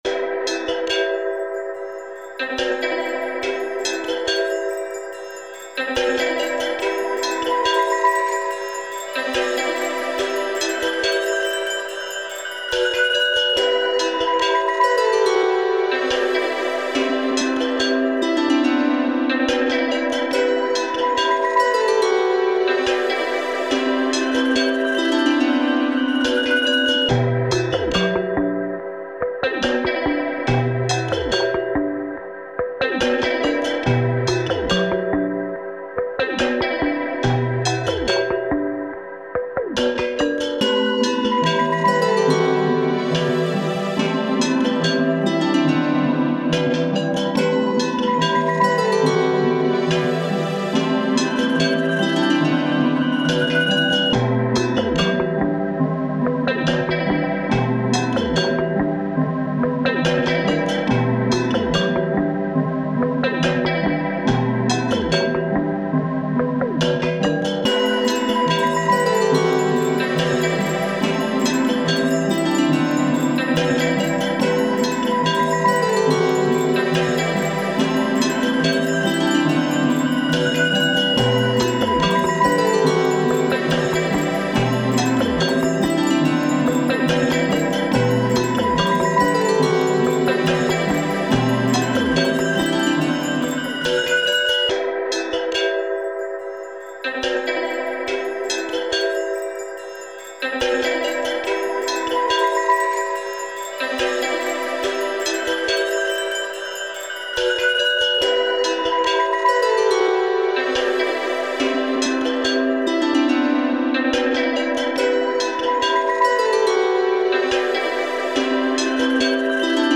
古びた図書館や魔法工房的な怪しい雰囲気が漂うファンタジー系の世界観にマッチする。